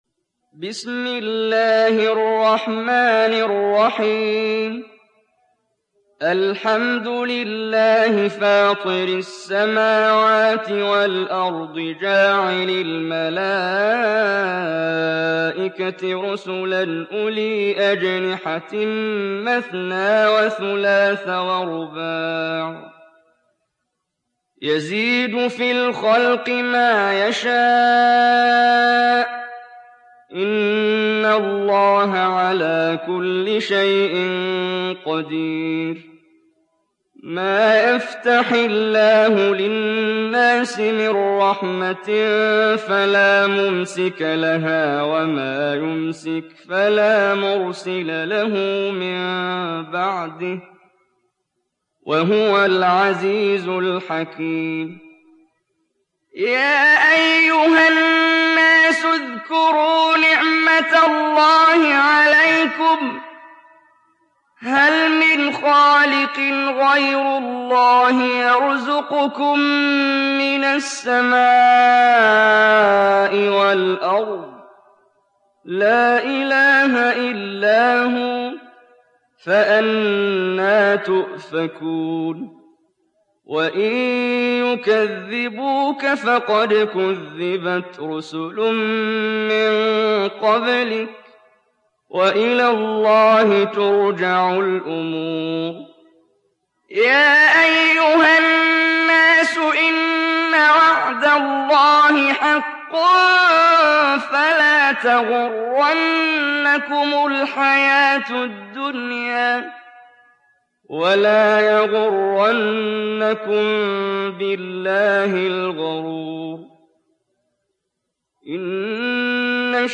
دانلود سوره فاطر mp3 محمد جبريل روایت حفص از عاصم, قرآن را دانلود کنید و گوش کن mp3 ، لینک مستقیم کامل